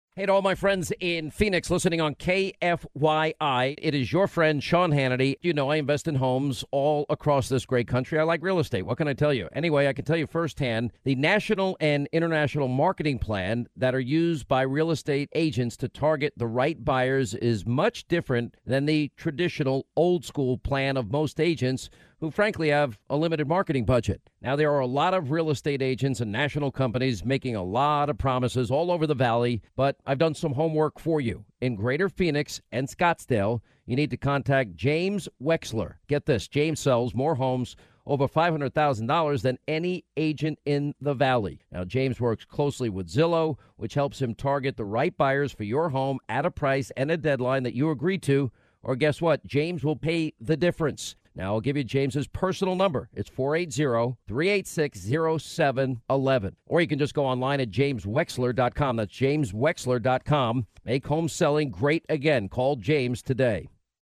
Celebrity Real Estate Agent Endorsements (Celebrity Cameos for Realtors) Celebrity Endorsement Agency | Radio & Television Experts